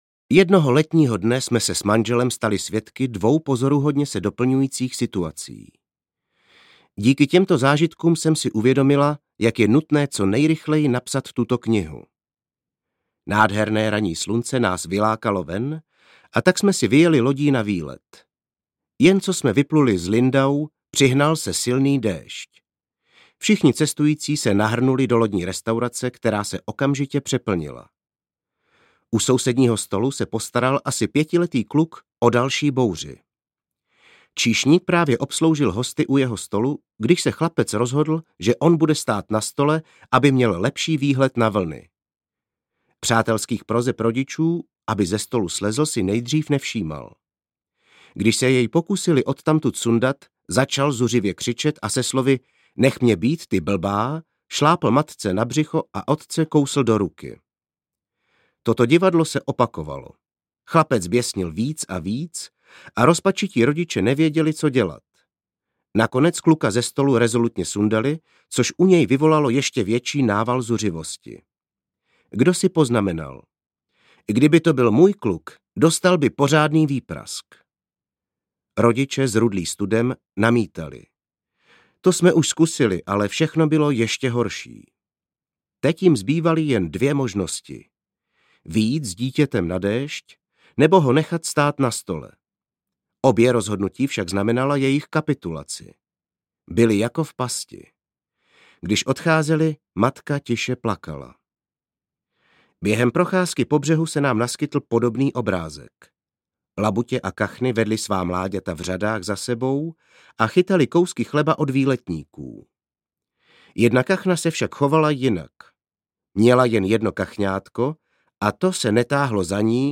Malý tyran audiokniha
Ukázka z knihy